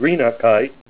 Say GREENOCKITE Help on Synonym: Synonym: ICSD 60629   PDF 41-1049